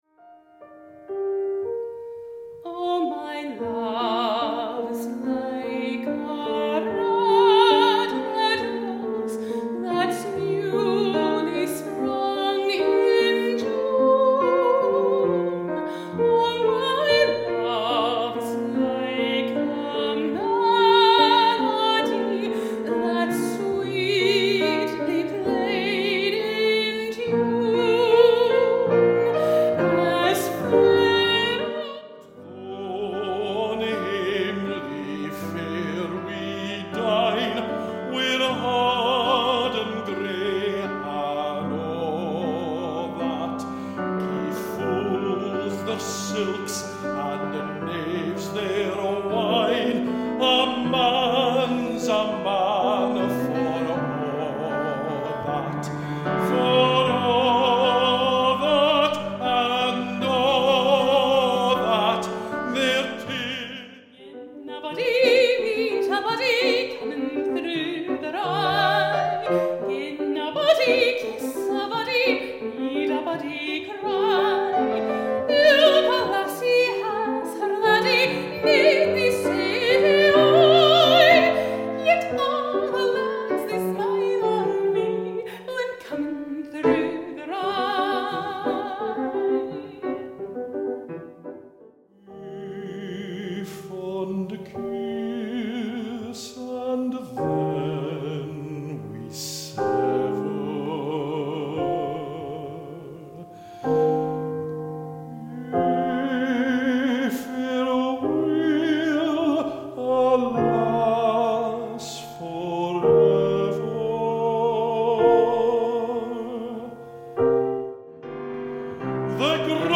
Voicing: Medium Voice